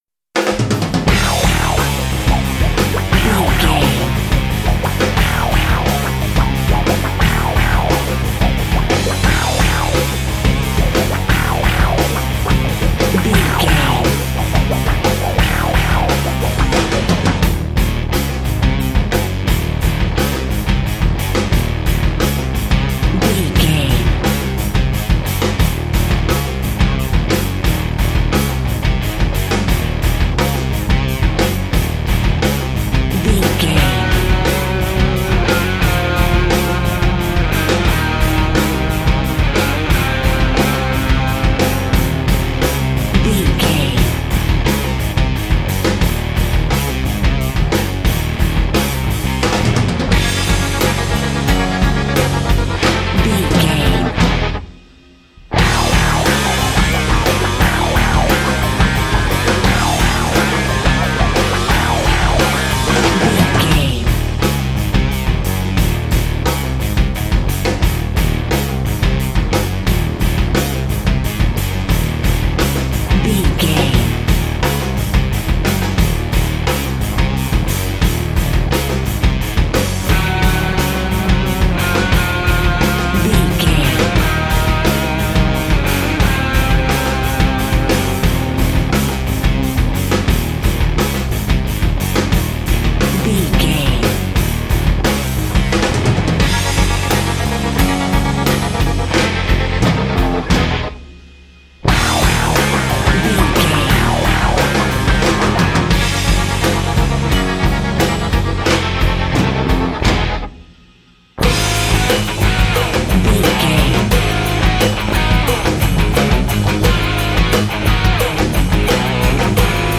Ionian/Major
electric guitar
drums
bass guitar
synthesiser
hard rock
lead guitar
aggressive
energetic
intense
nu metal
alternative metal